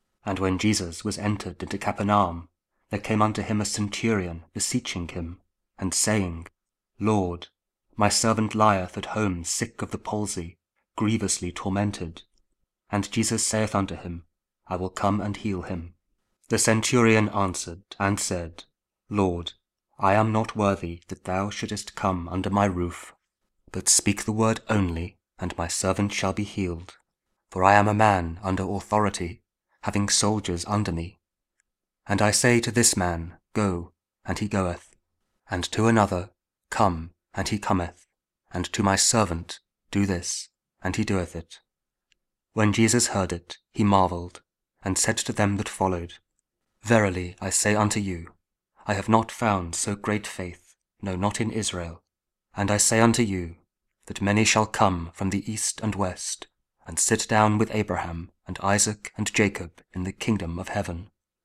King James Audio Bible | KJV | King James Version | Matthew 8: 5-11 | Daily Bible Verses For Advent